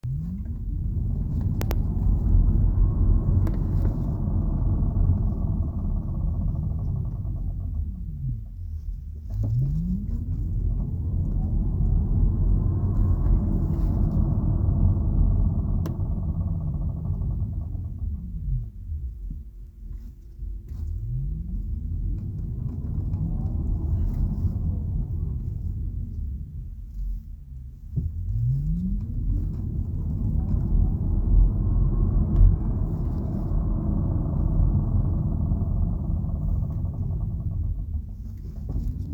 Symptoms: Faint clicking from rear axles during 1-pedal deceleration (10-20mph).